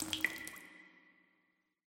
amb_waterdrip_single_06.mp3